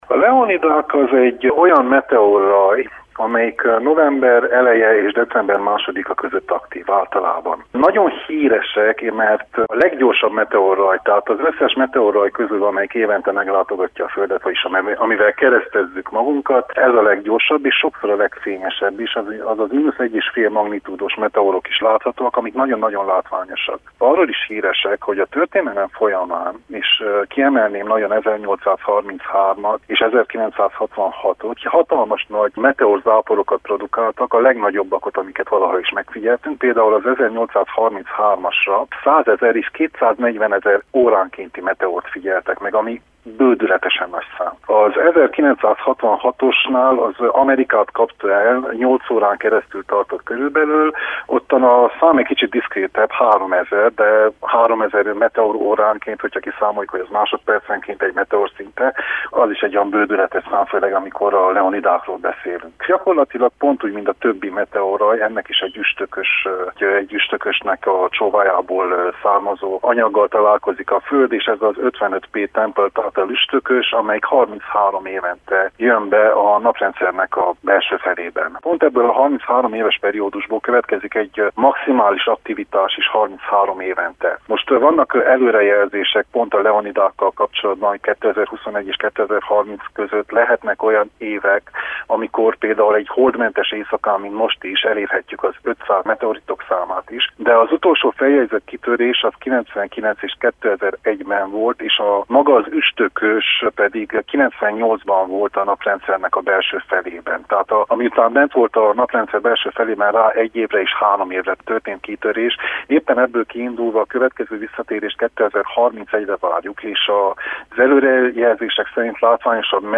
amatőr csillagászt